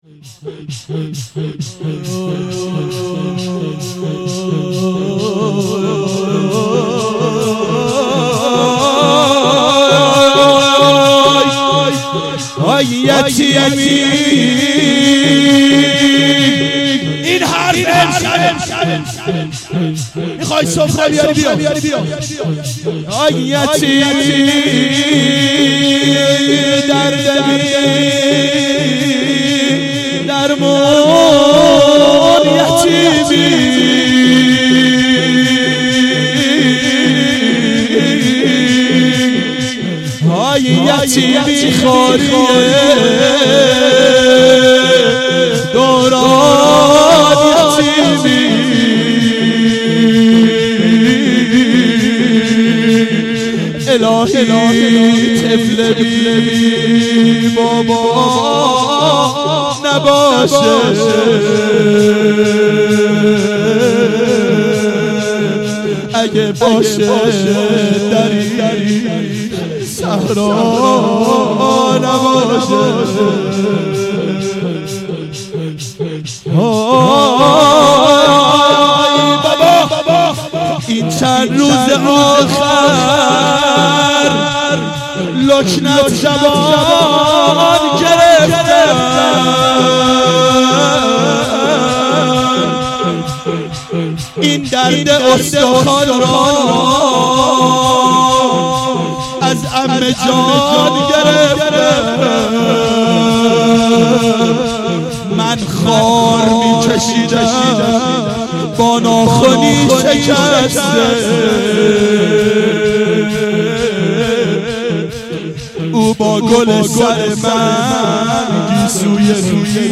دشتی
هیئت شیفتگان حضرت رقیه سلام الله علیها (شب اشهادت)